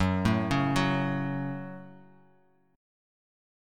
F#m Chord
Listen to F#m strummed